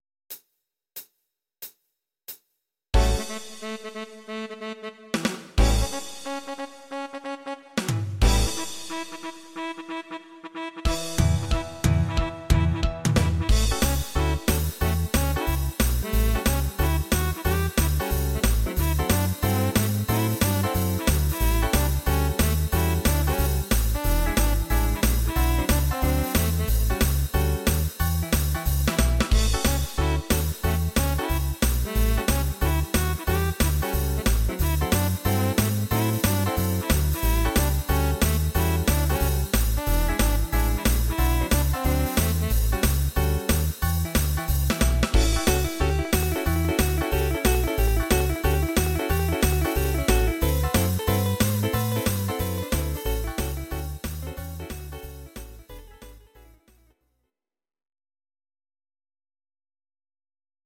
Audio Recordings based on Midi-files
Pop, Oldies, 1950s